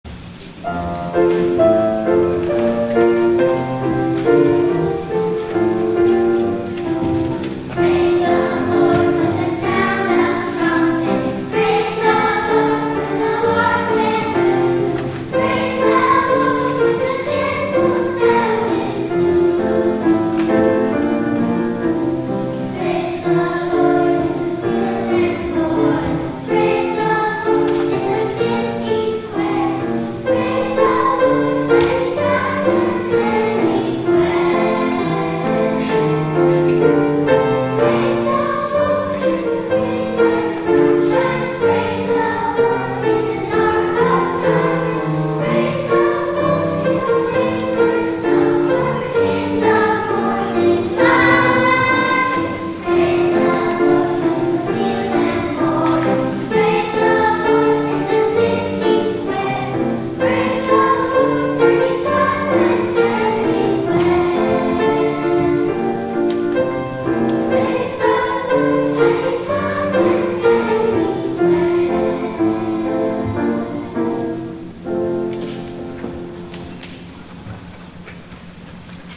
THE INTROIT
Cherub Choir